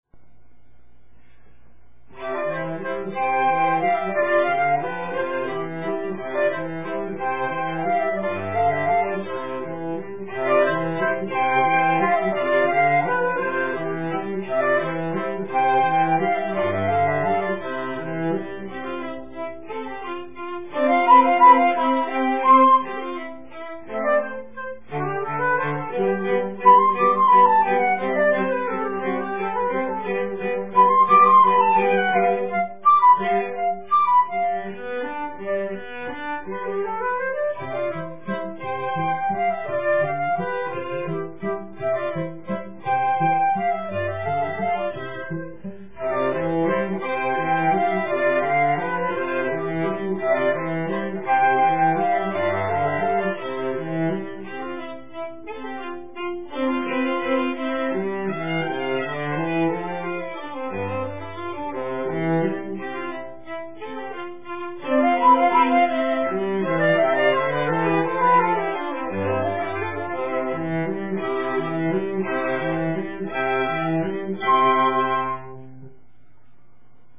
Vivace